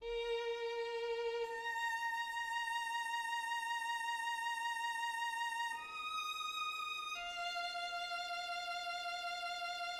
First movement: Moderato
The first movement, in D minor, is in sonata form.